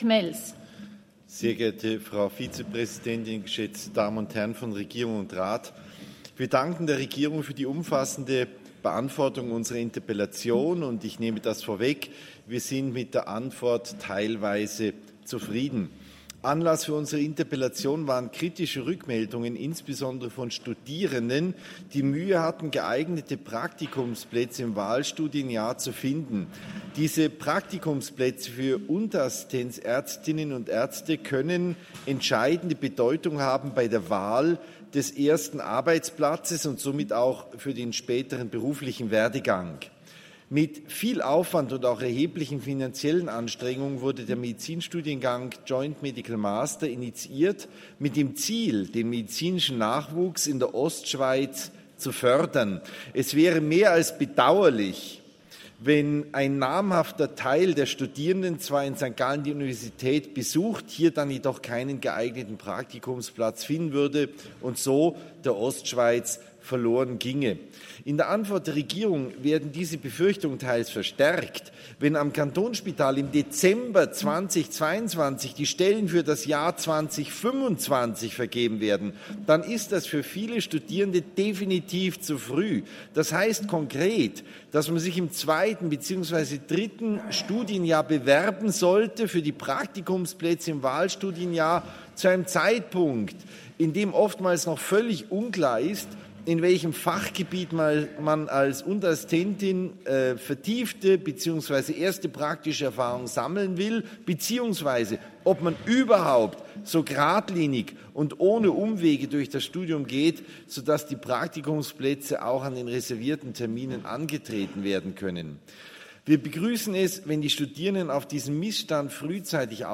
21.9.2022Wortmeldung
Session des Kantonsrates vom 19. bis 21. September 2022